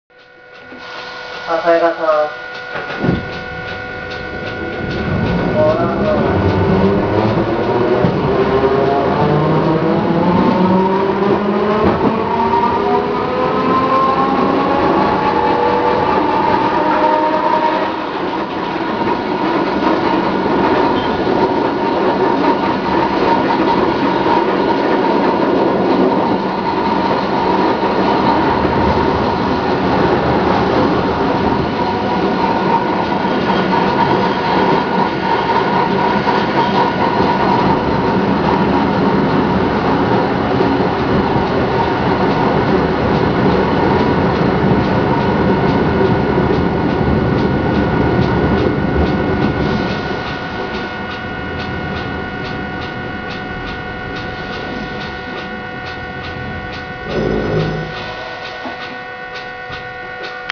・50形走行音
【城南線】道後公園→南町A（1分1秒：330KB）…73号にて
一応前・中・後期で分けたのですが基本的に音は同じで、全て吊り掛け式。個人的には、数ある路面電車の中でもかなり派手な音を出す部類に感じました。